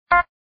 CountDown.MP3